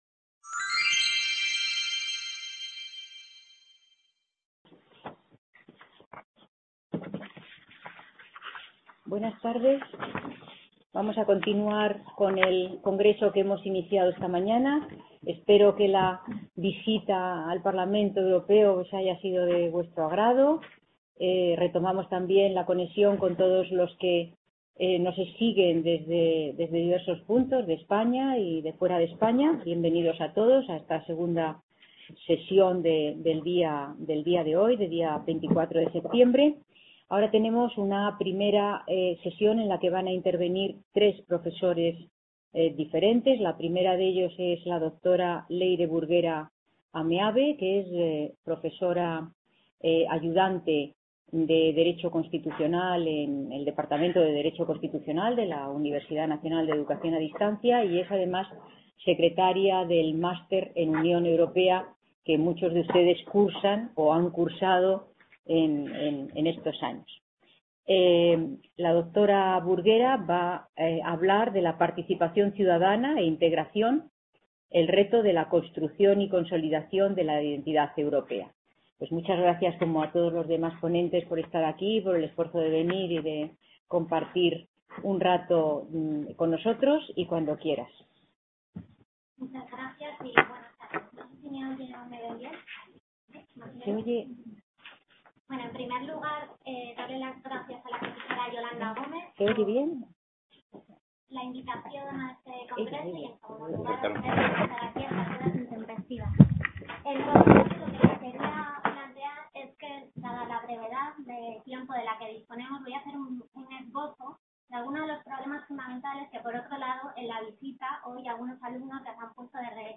C.A. Bruselas - IV Congreso Internacional sobre Unión Europea: nuevos problemas, nuevas soluciones.